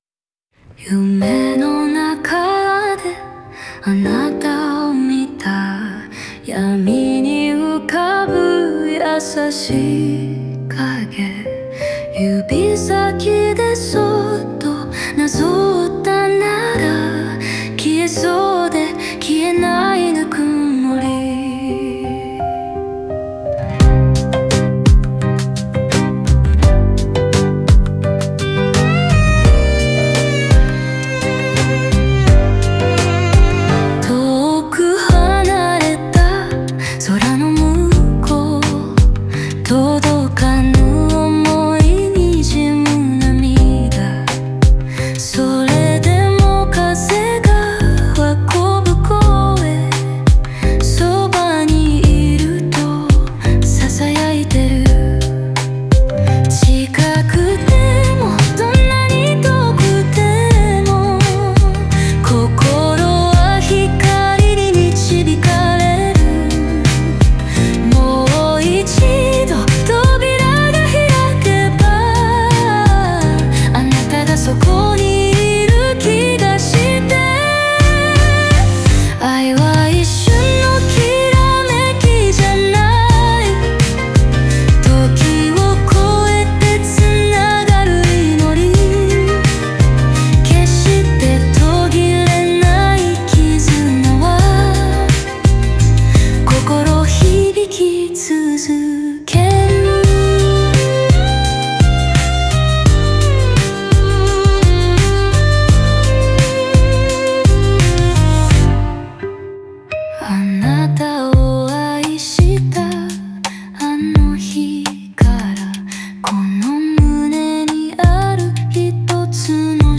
オリジナル曲♪
切なくも温かい、普遍的な愛の物語が、バイオリンのメロディに乗せて紡がれるような歌詞です。